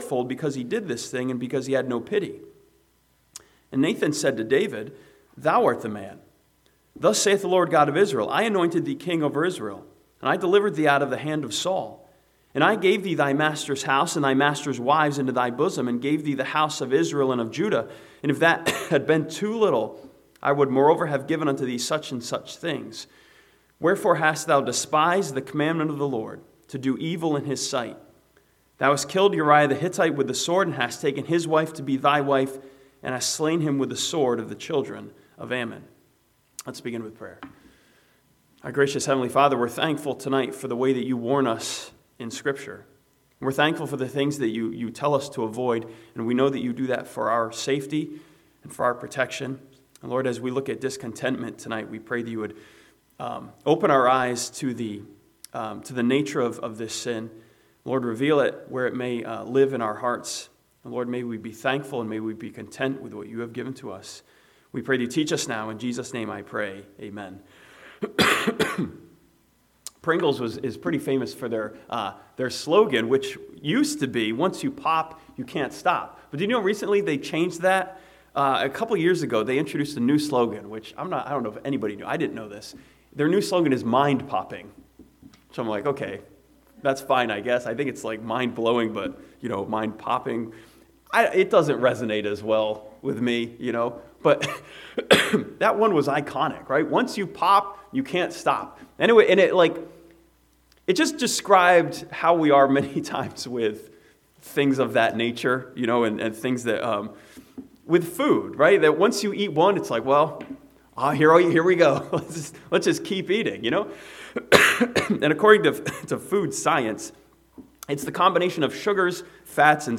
This sermon from 2 Samuel 12 studies David as a portrait of discontentment which took him further than he wanted to go.